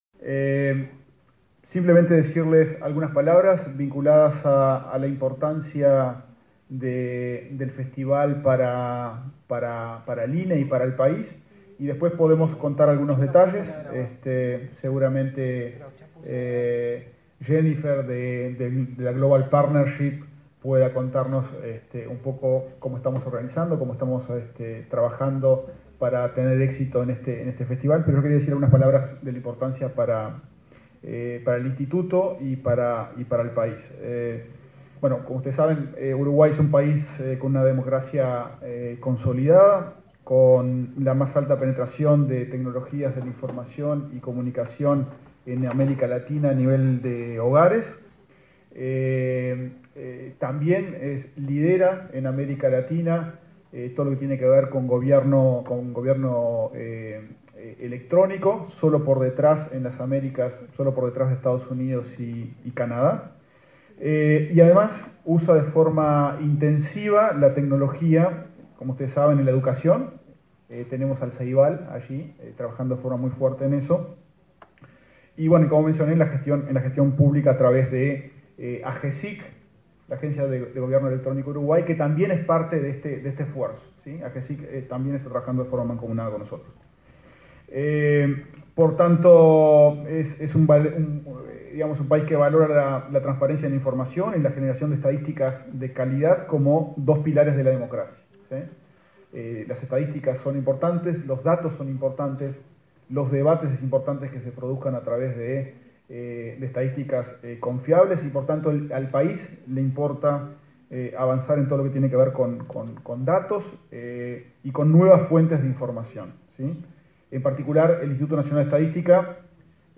Palabras de autoridades en lanzamiento de Festival de Datos 2023
El director del Instituto Nacional de Estadística (INE), Diego Aboal, y el subsecretario de Turismo, Remo Monzeglio, participaron en el acto de